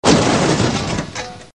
Incidente auto 1
Rumore di urto tra metalli con tintinnio finale.